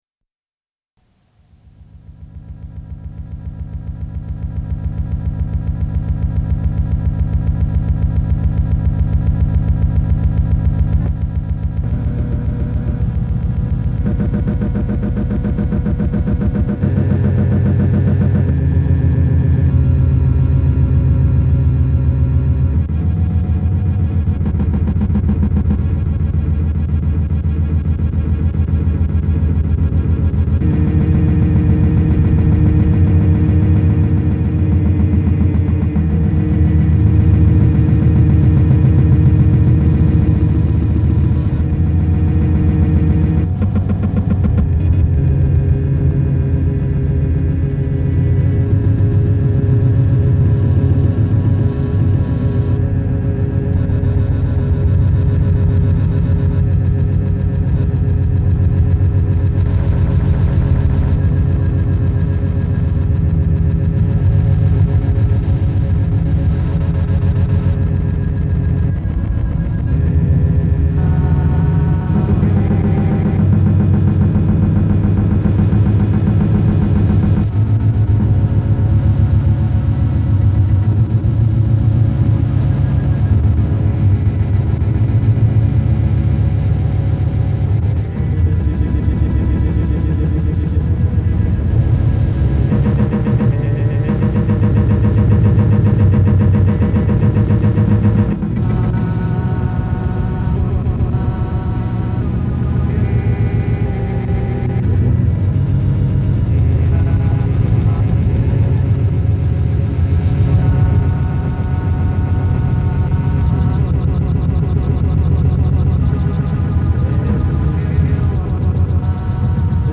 I haven’t cleaned it up at all, so beware of tape hiss.